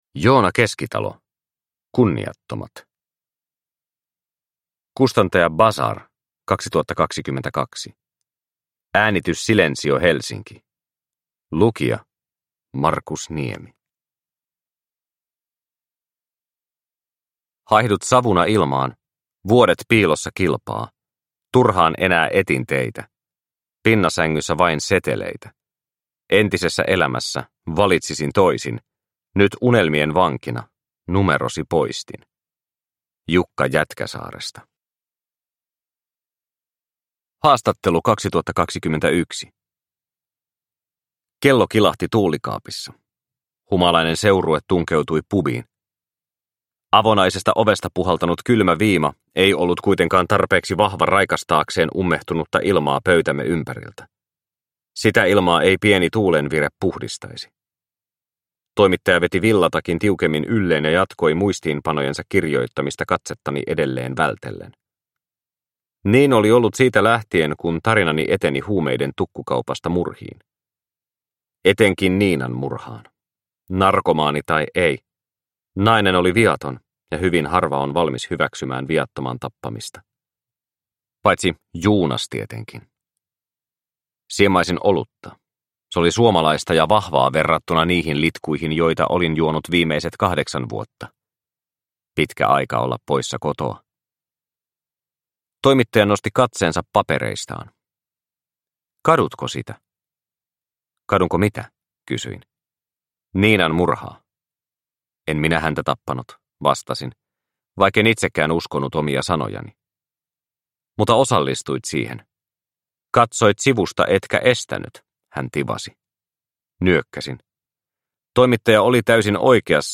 Kunniattomat – Ljudbok – Laddas ner